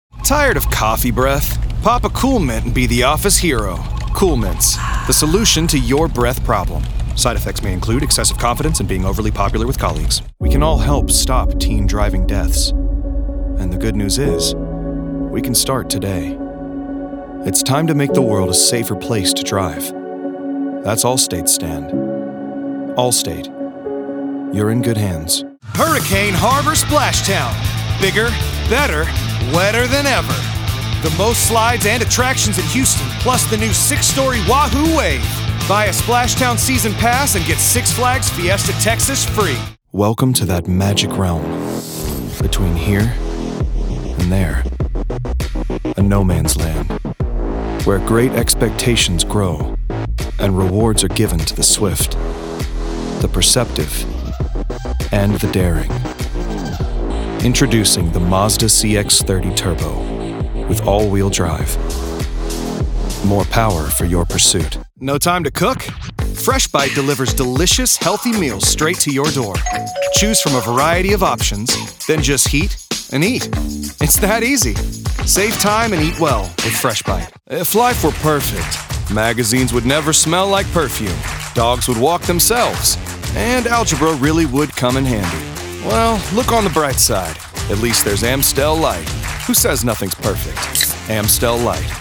Teenager, Young Adult, Adult, Mature Adult
Has Own Studio
COMMERCIAL 💸